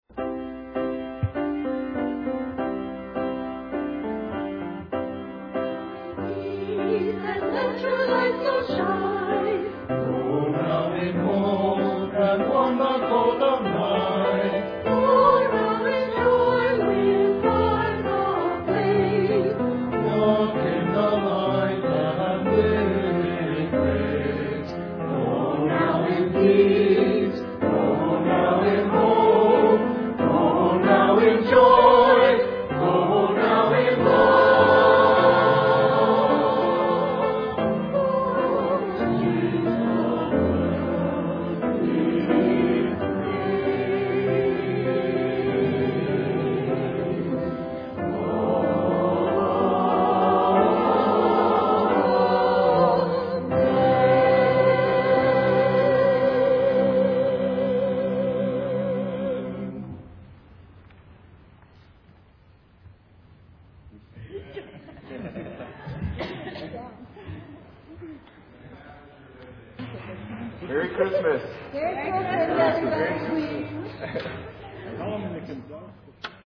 Sunday Service
Christmas Cantata